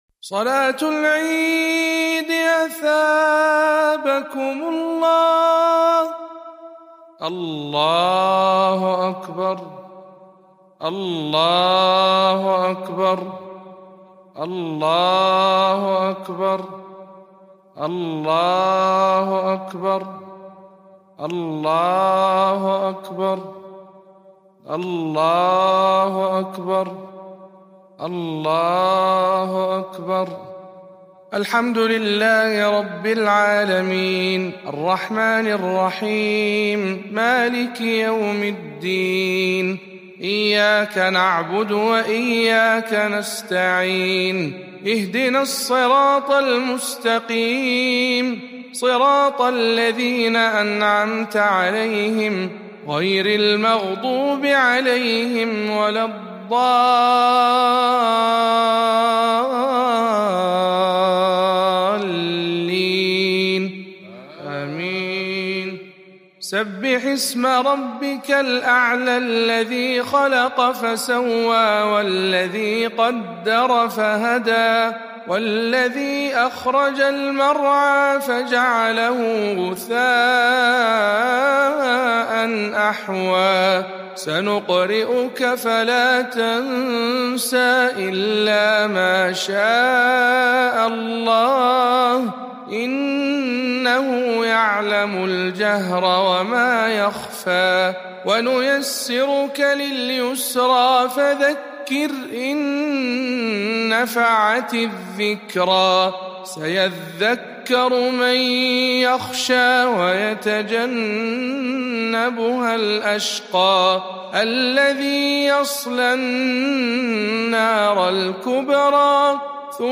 صلاة عيد الفطر المبارك